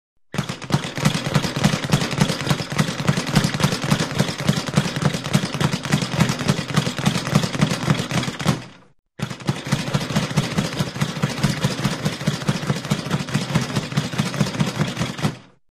Звуки бокса
Звук подвесной груши быстро